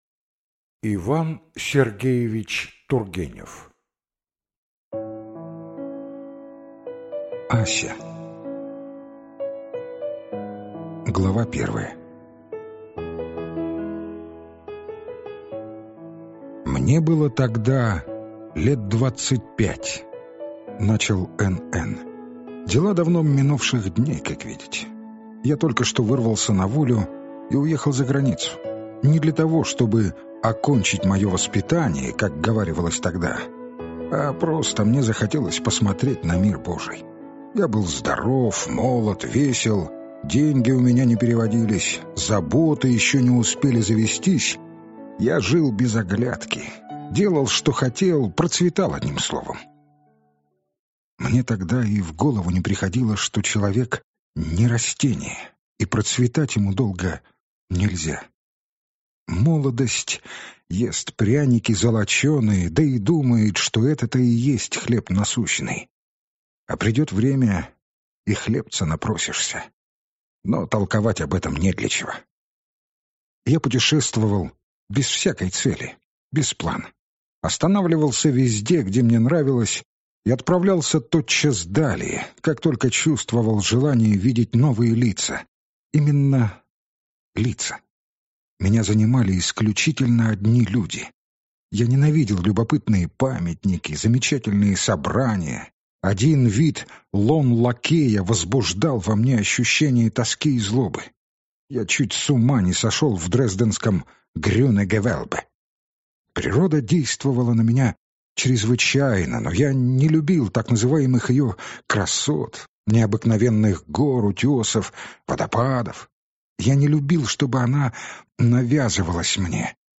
Аудиокнига Ася. Первая любовь. Вешние воды | Библиотека аудиокниг